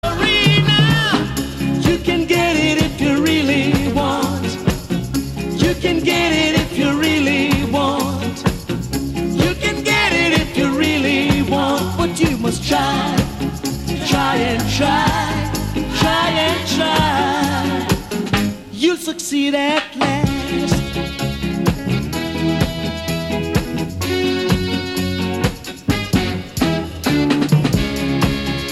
Tonart: B-Dur
Besetzung: Blasorchester